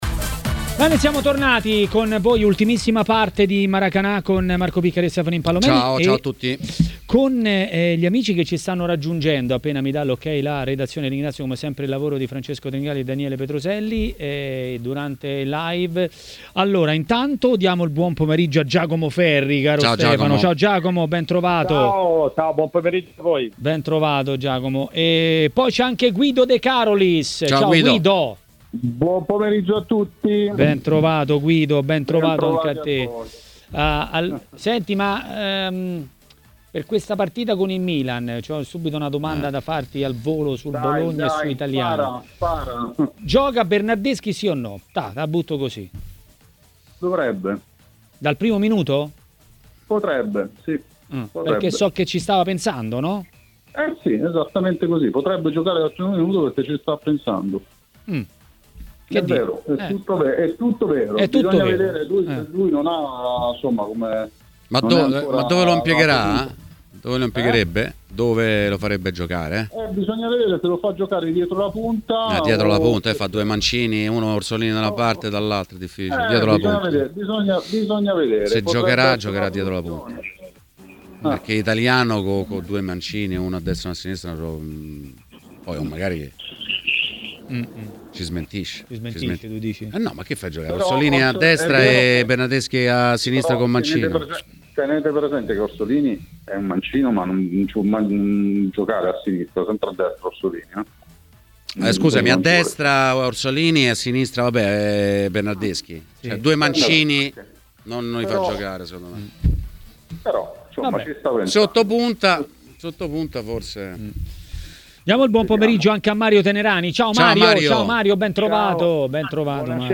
In diretta su TMW Radio, spazio a Giacomo Ferri.